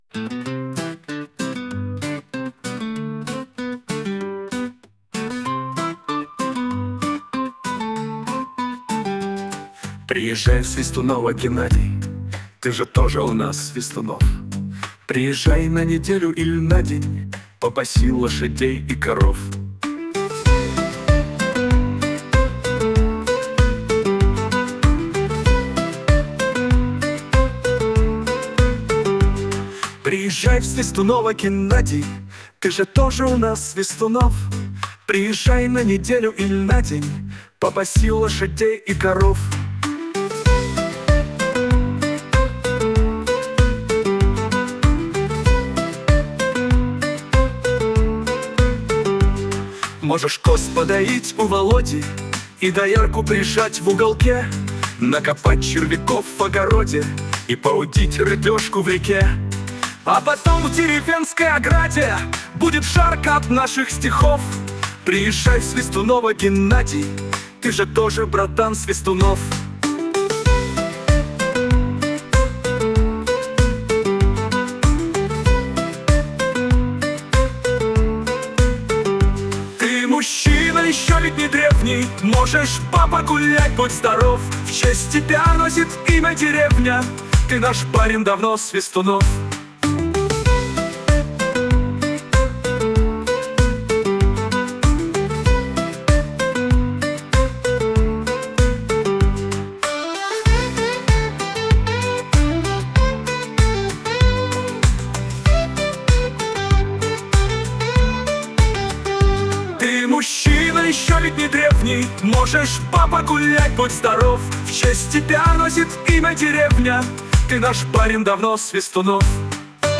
шуточная песня